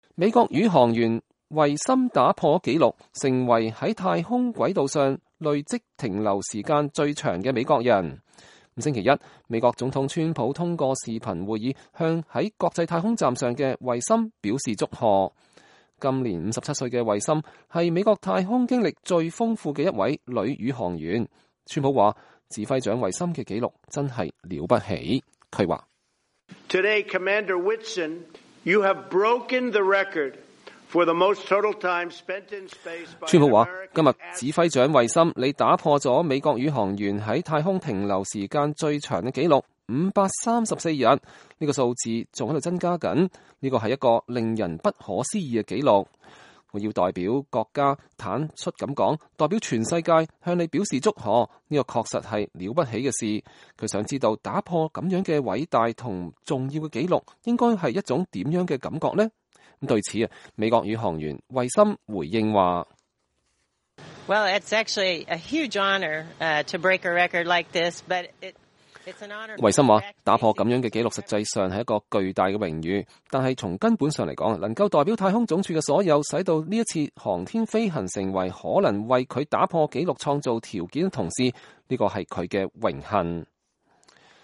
美國女宇航員佩吉惠森在國際太空站上接受採訪